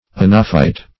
Search Result for " anophyte" : The Collaborative International Dictionary of English v.0.48: Anophyte \An"o*phyte\ ([a^]n"[-o]*f[imac]t), n. [Gr.